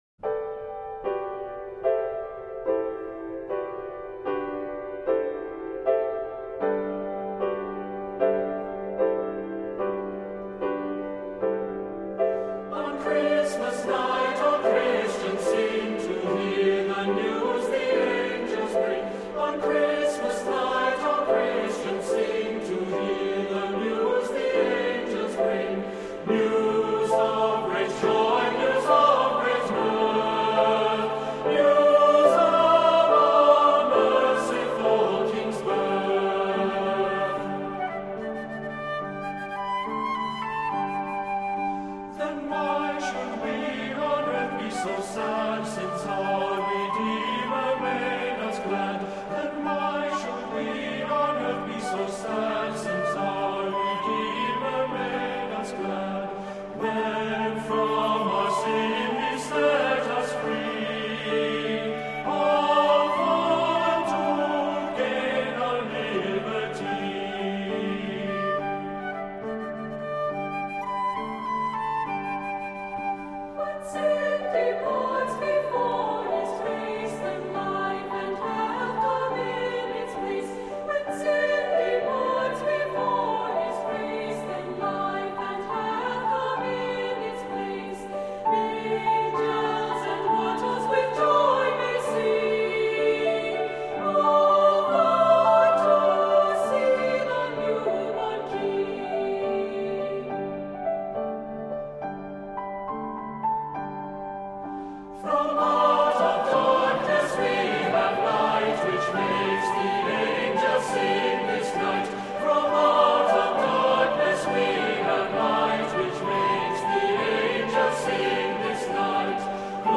Voicing: 2-Part and Piano